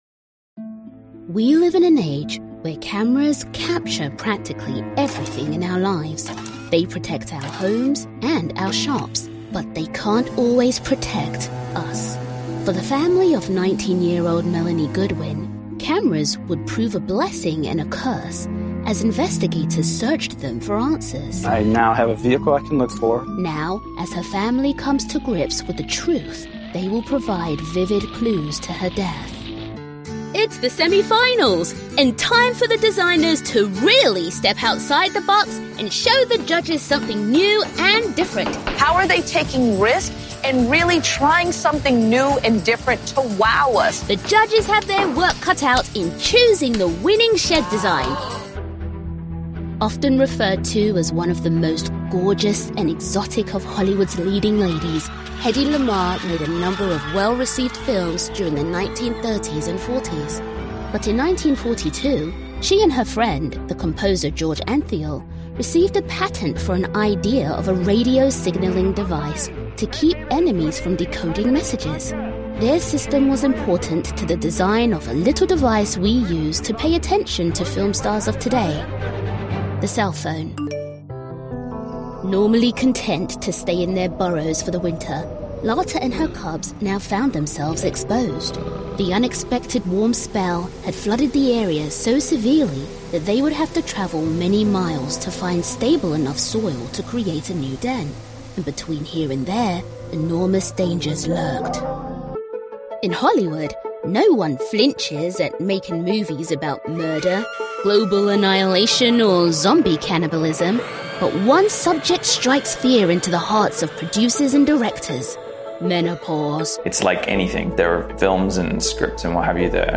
Female
British English (Native)
Bright, Bubbly, Character, Children, Versatile, Young, Conversational, Cool, Energetic, Engaging, Funny, Posh, Smooth, Soft, Upbeat, Approachable, Confident, Corporate, Friendly, Natural, Warm, Cheeky, Gravitas, Reassuring, Sarcastic, Streetwise, Witty
British (native), Cockney, European, African (Native), European, American
Microphone: Sennheiser MKH 416
Audio equipment: Whisperroom, Treated,